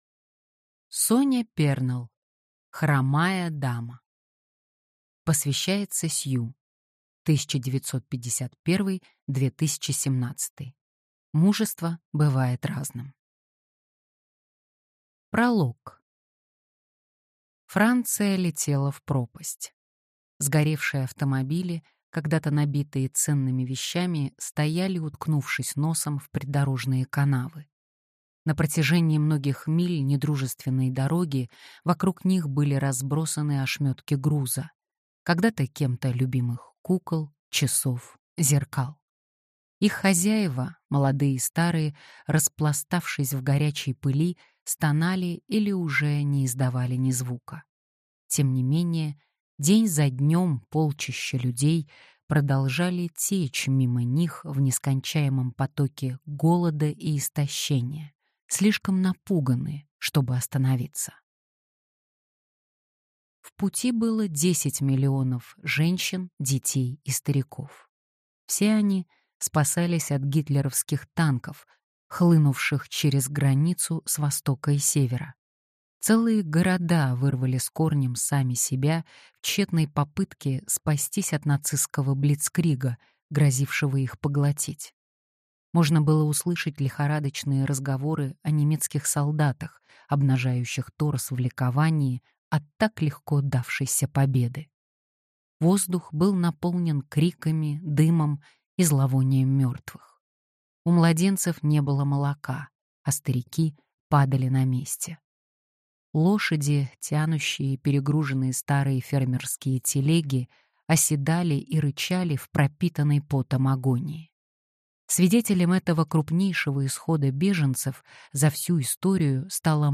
Аудиокнига Хромая дама: Нерассказанная история женщины – тайного агента периода Второй мировой войны | Библиотека аудиокниг